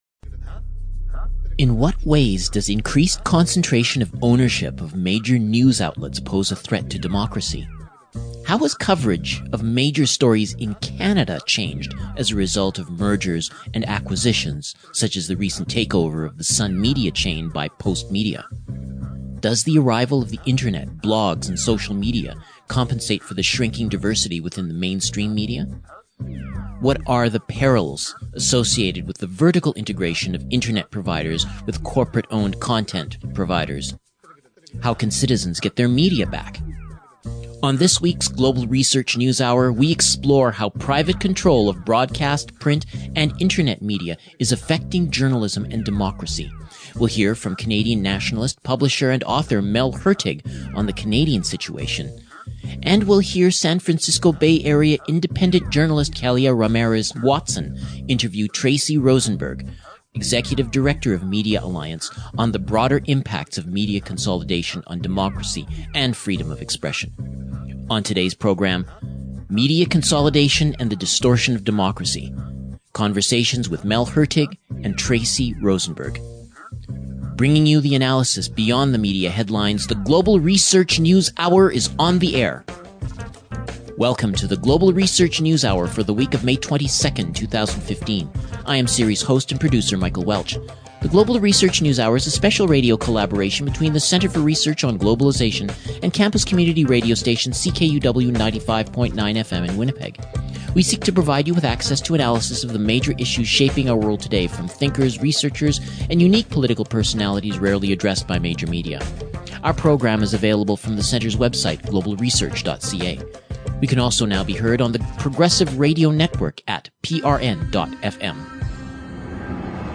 Guests discuss inpacts of Concentration of media ownership in Canada and the US